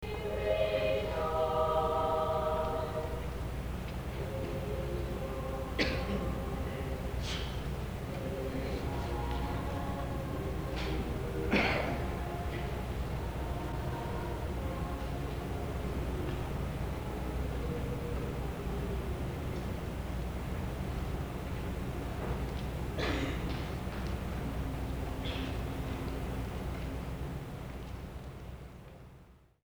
Christmas Concert 1972
Clay High Gym